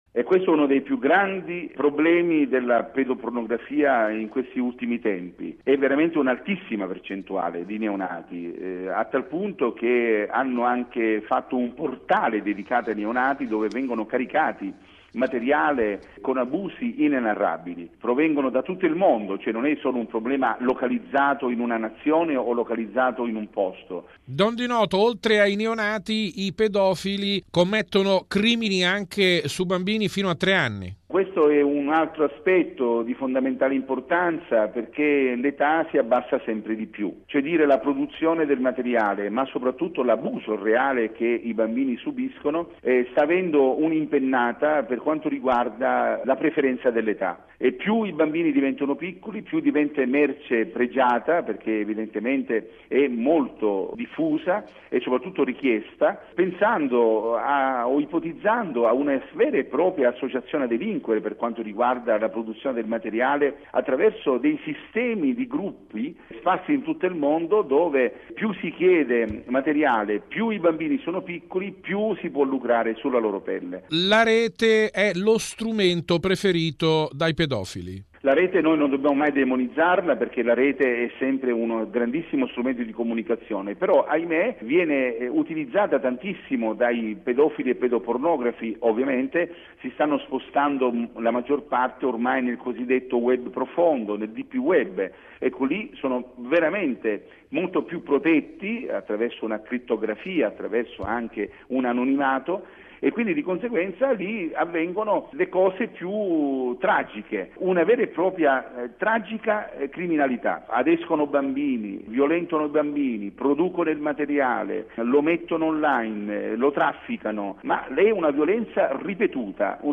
Bollettino Radiogiornale del 20/03/2017